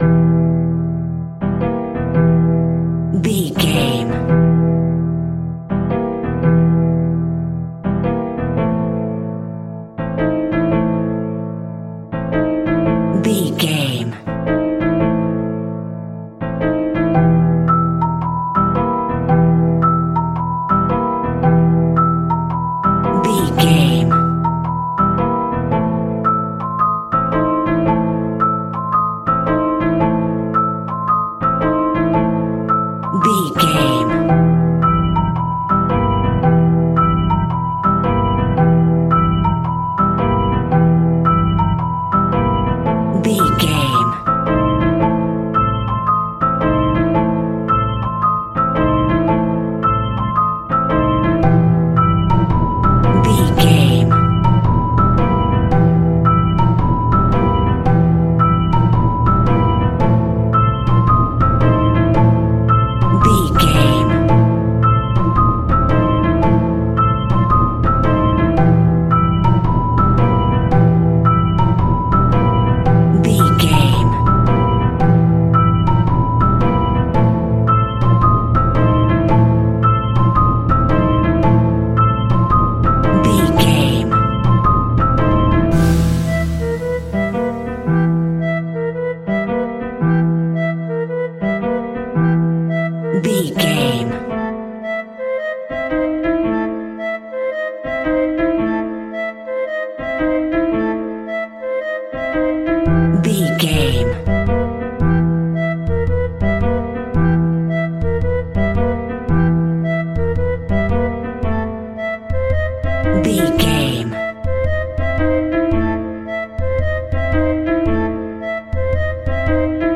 Aeolian/Minor
scary
ominous
haunting
eerie
piano
strings
organ
flute
percussion
spooky
horror music